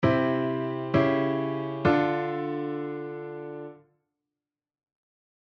パッシングディミニッシュ
↓の例は、C⇒Dm（I⇒IIｍ）の間をC#dimで繋いだパターンです。
C⇒C#dim⇒Dm